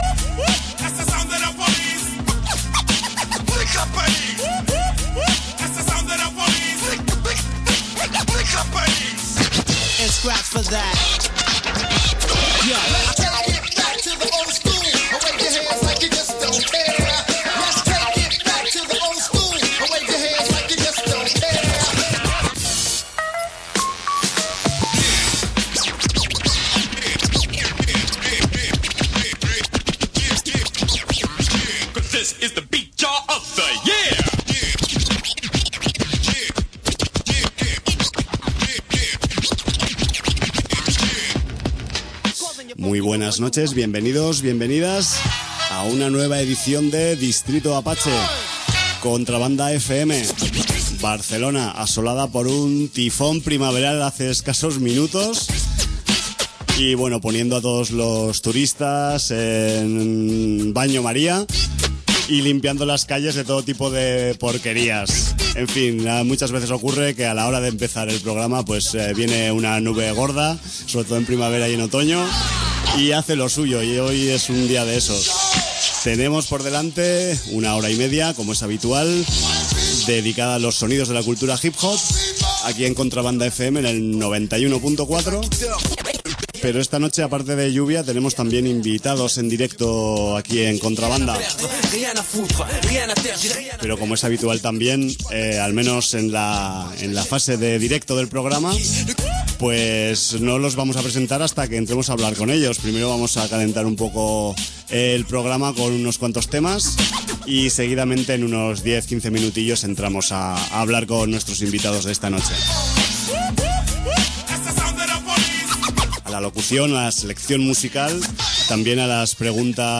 Hoy tenemos en el programa, la visita doble de 2 MC’s afincados en la escena de Barcelona, pero provenientes de las lejanas tierras americanas: